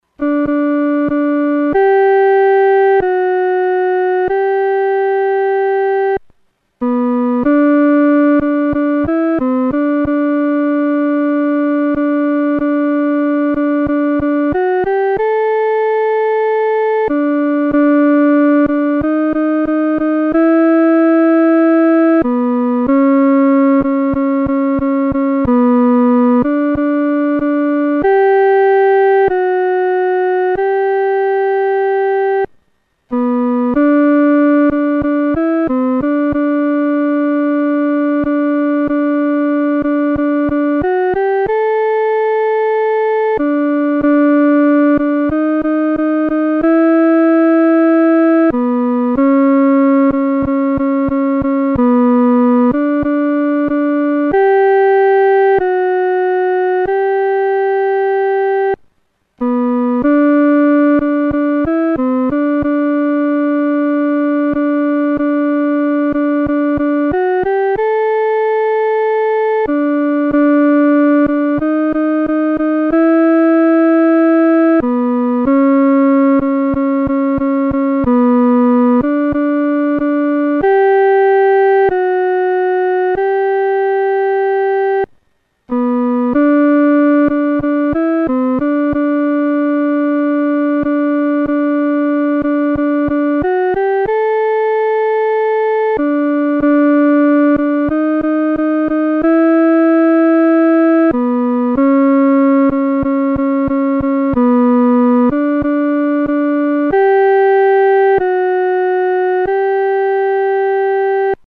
独奏（第二声）